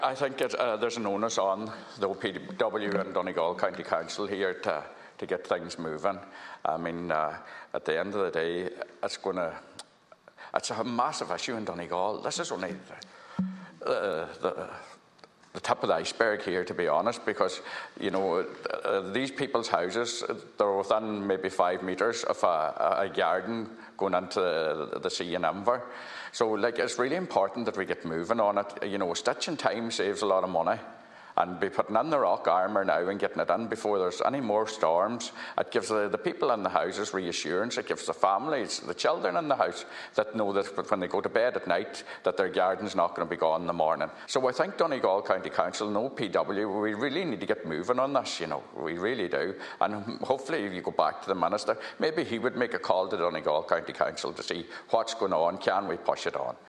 Speaking in the Seanad, Senator Boyle says people’s homes are at the brink of collapse and something must be done before another storm: